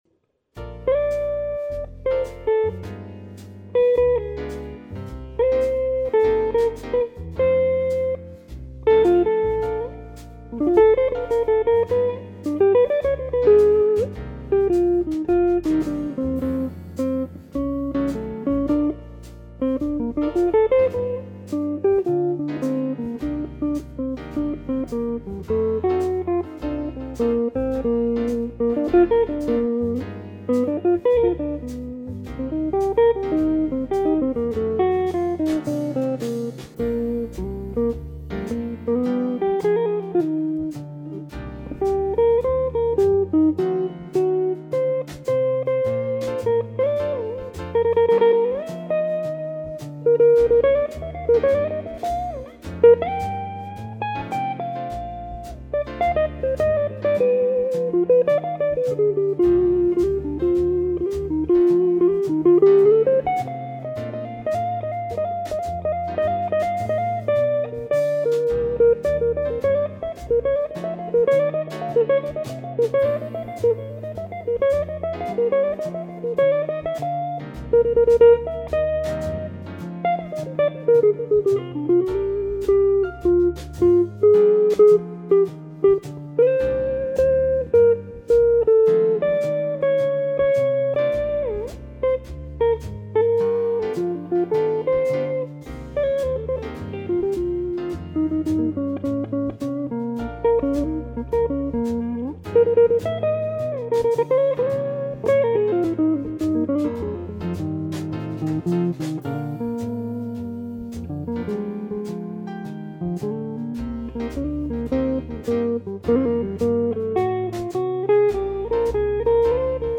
1.1.09 - I-VI-ii-V in Bb.mp3